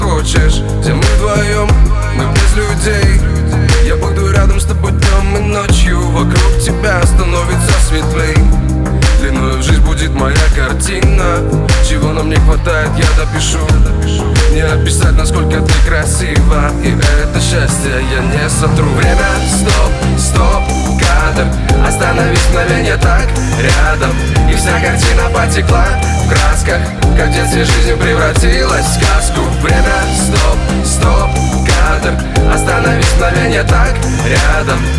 Жанр: Хаус / Русские